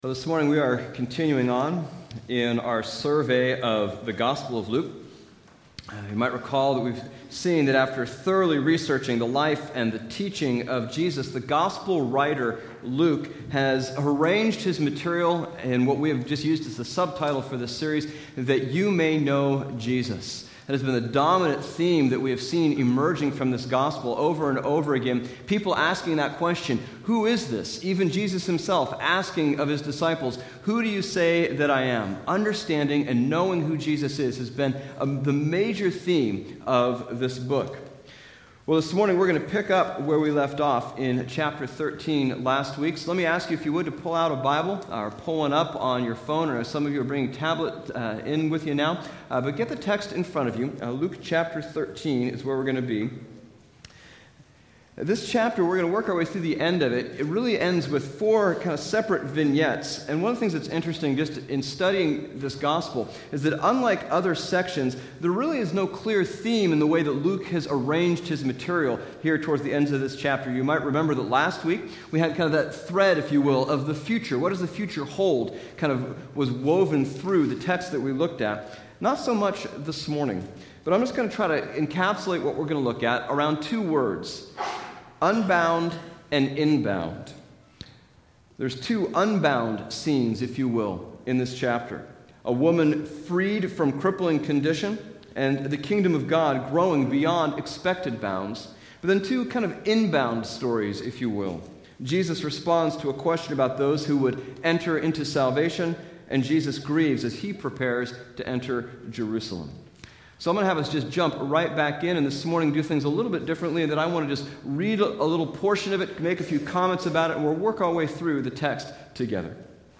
Bible Text: Luke 13:10-35 | Preacher